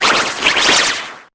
Cri de Rongrigou dans Pokémon Épée et Bouclier.